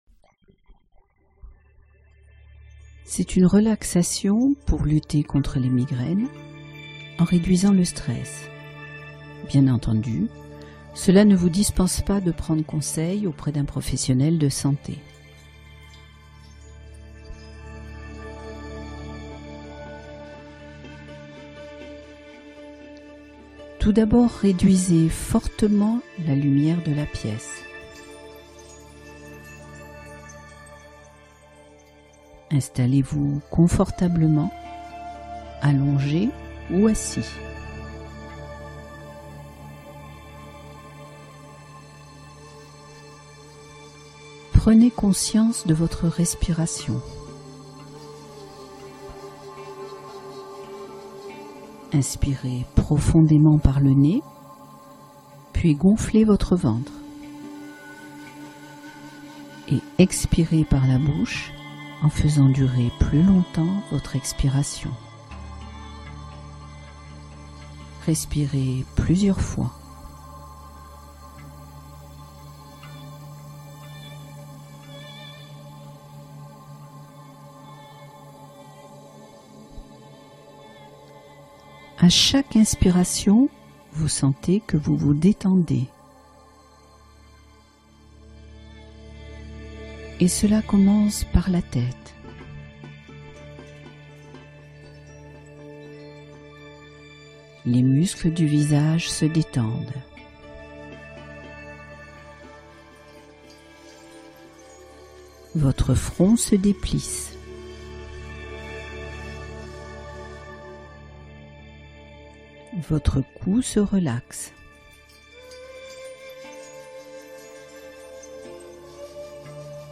Apaisement Mental : Relaxation guidée pour soulager les tensions de la tête